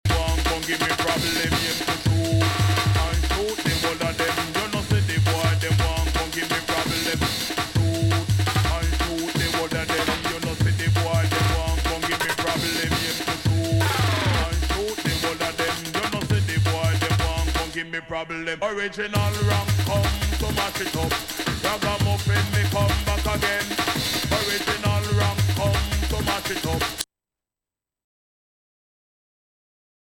Drum & Bass / Jungle